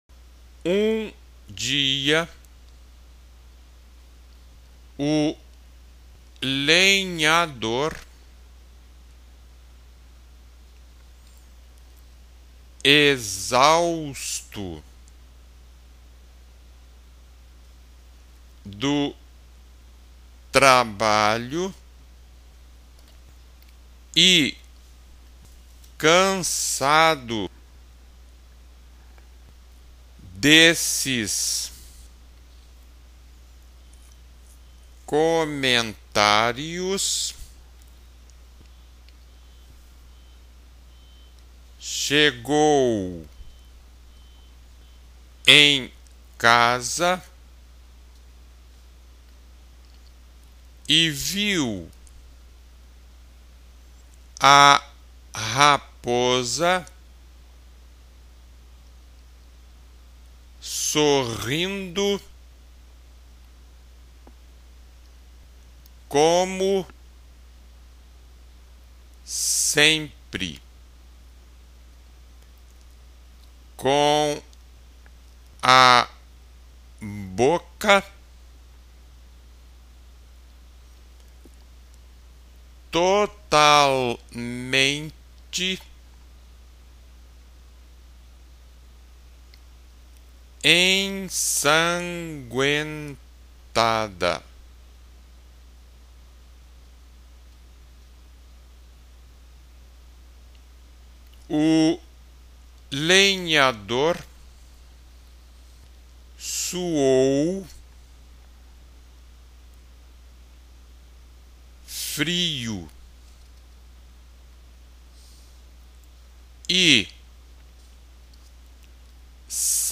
II) SEGUNDO DITADO-TESTE: (ATENÇÃO: Este ditado está dividido em três partes!)